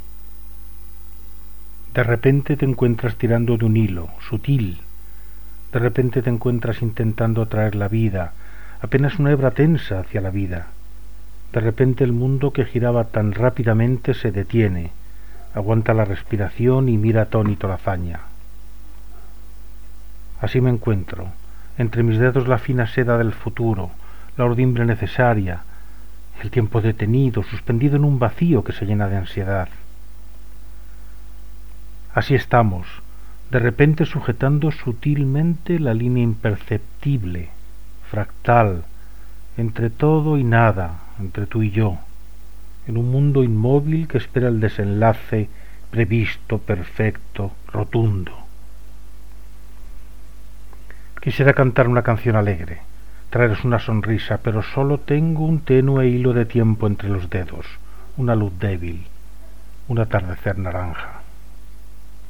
Lectura del poema Una luz tenue